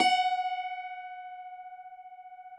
53r-pno16-F3.aif